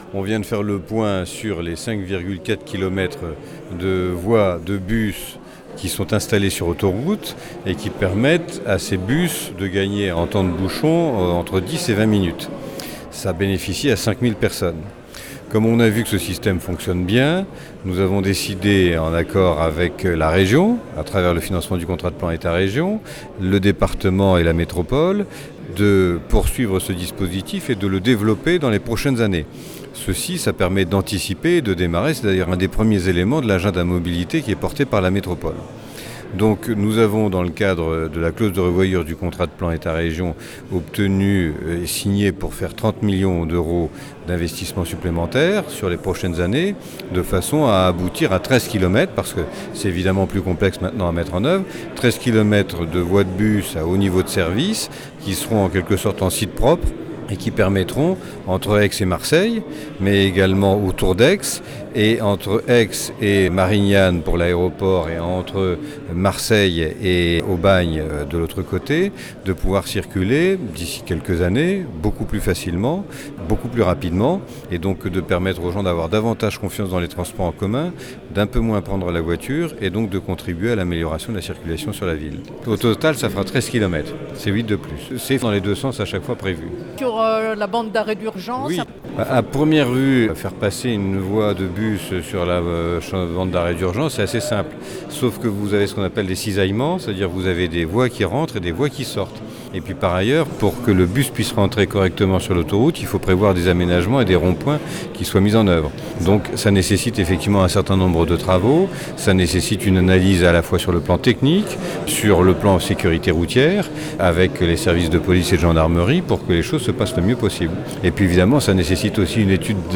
ste-215_prefet_bouillon.mp3